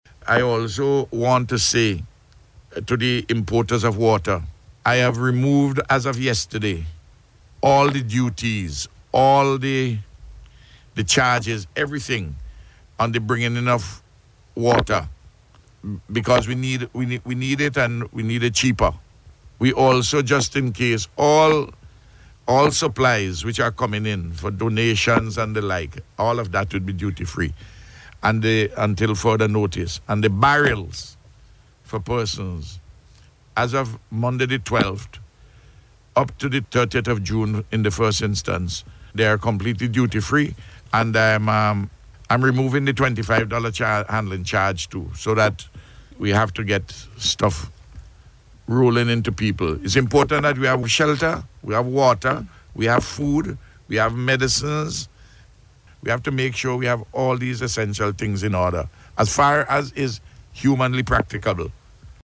Speaking on NBC Radio this morning, the Prime Minister said a meeting was held with all stakeholders yesterday to discuss ongoing relief effort.